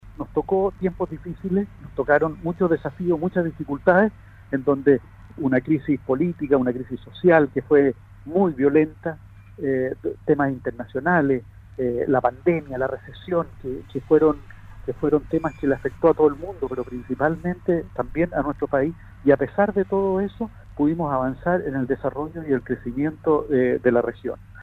En conversación con Radio Sago, el Delegado Presidencial regional Carlos Geisse realizó un balance de su gestión, luego que en enero del 2021 llegará a ocupar el cargo de Intendente regional que dejó Harry Jurgensen. En la oportunidad, la autoridad regional fijó su posición respecto a la figura de Delegado Presidencial Regional acotando que al menos con la constitución actual es un cargo que se necesita.